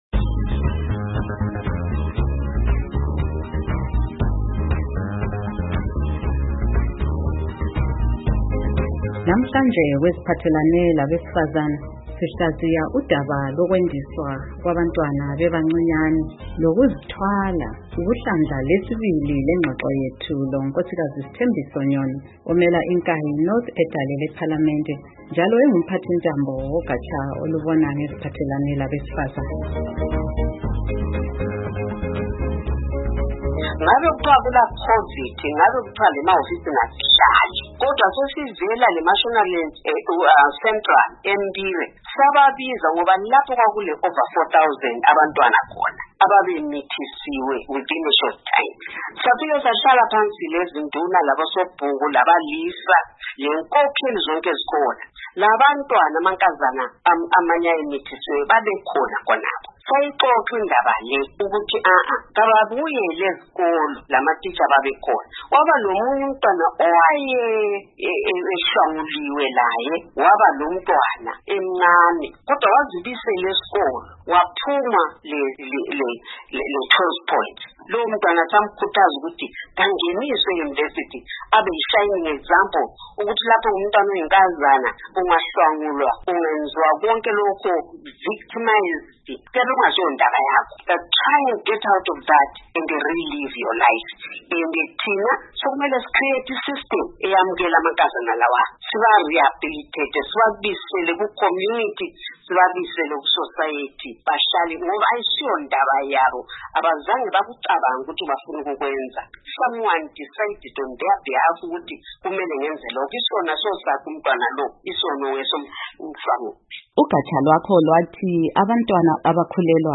Ingxoxo loNkosikazi Sithembiso Nyoni